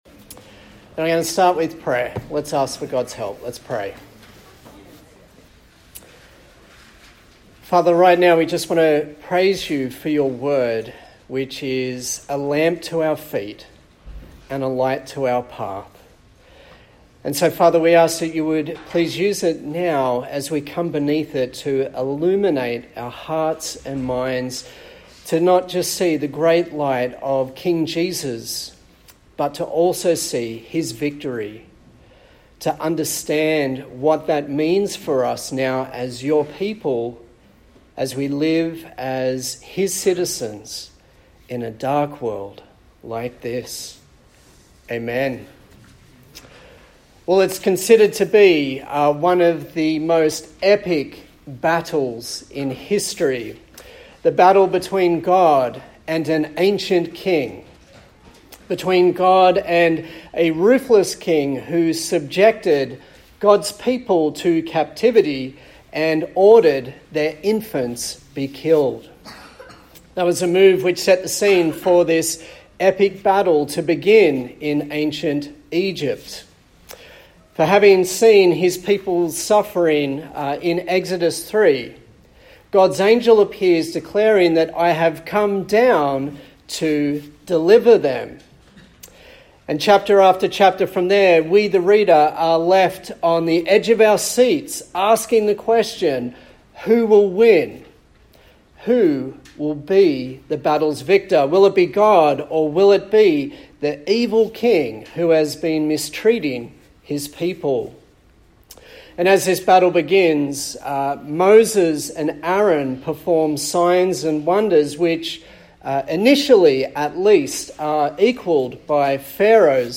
Acts Passage: Acts 12:1-25 Service Type: Sunday Morning